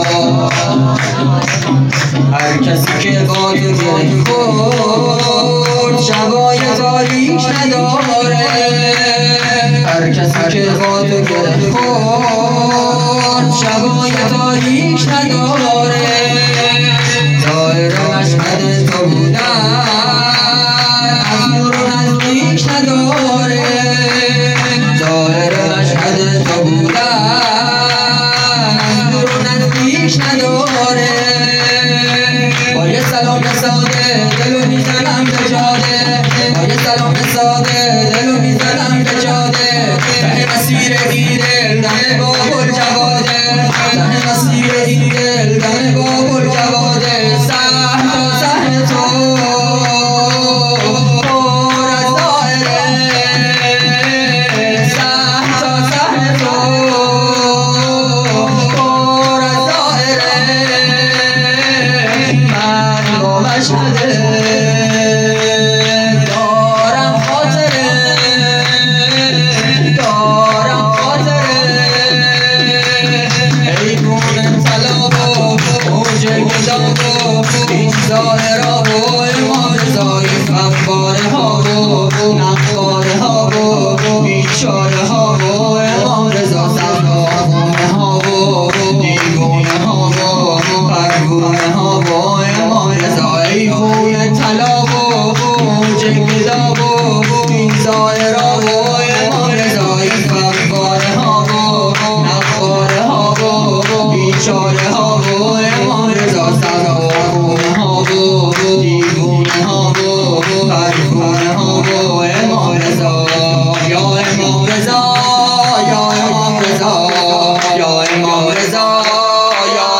سرود
ولادت امام رضا(ع)